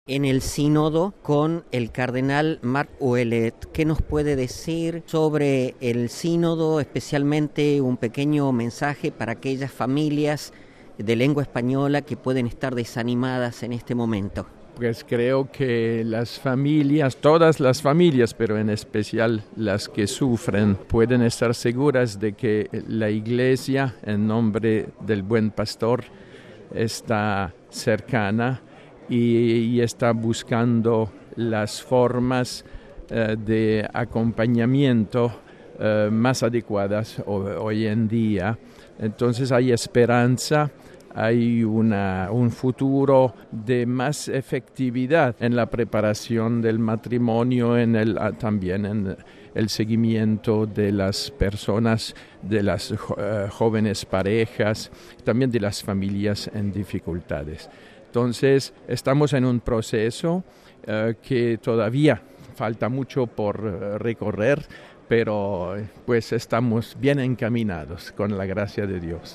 MP3 El Cardenal Marc Ouellet lanza durante el Sínodo de los Obispos, a través del micrófono de Radio Vaticana, un mensaje a todas las familias del mundo, pero especialmente a las “que más sufren”, y asegura que pueden estar seguras de que la Iglesia “en nombre del buen Pastor, está cerca y está buscando las formas de acompañamiento más adecuadas.